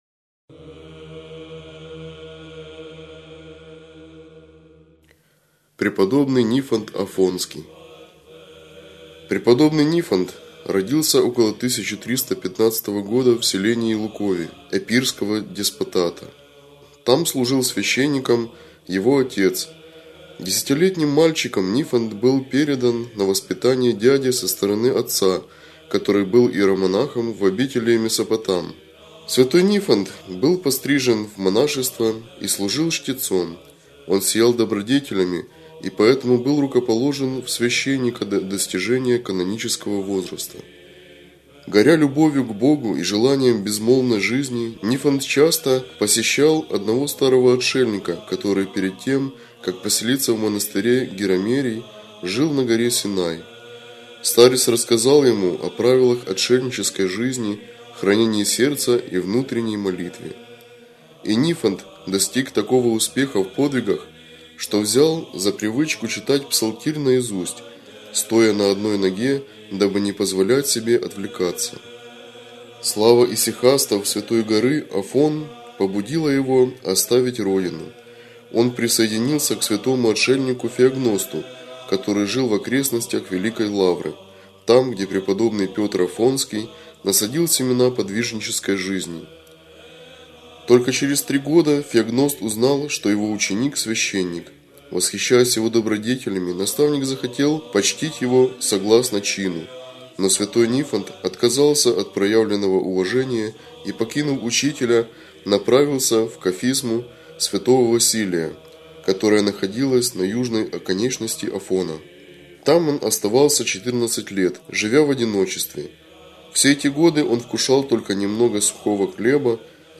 Читает иеромонах